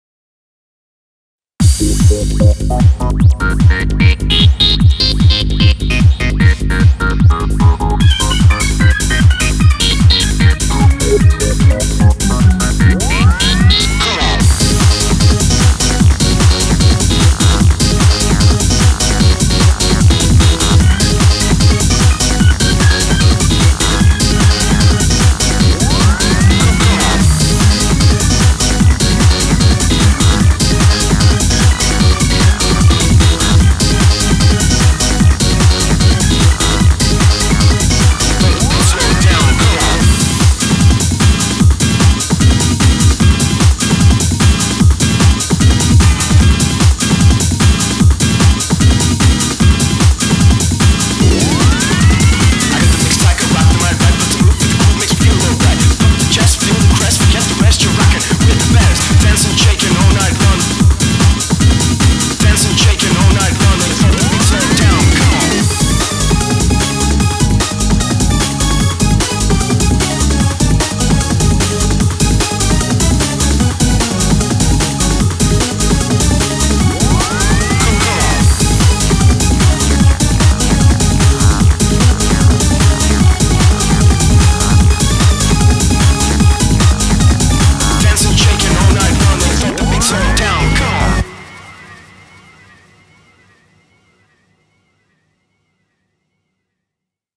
BPM150
Audio QualityMusic Cut